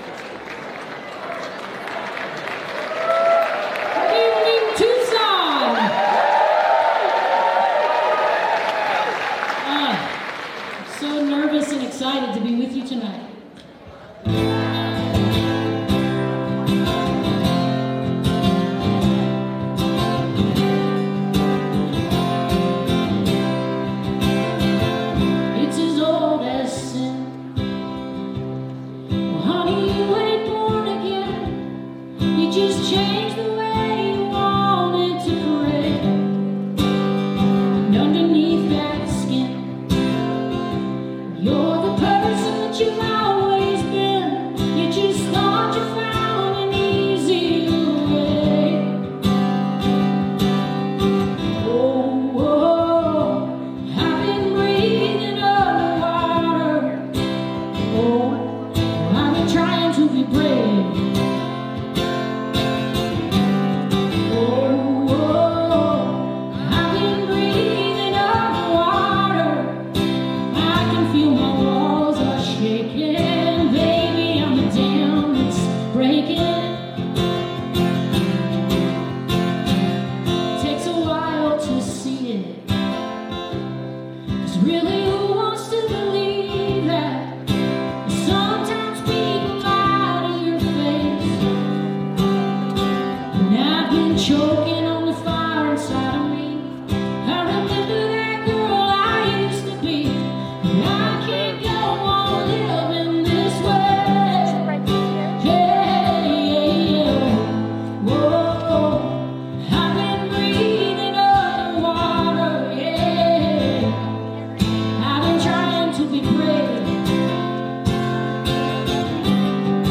(band show)
(captured from a web stream)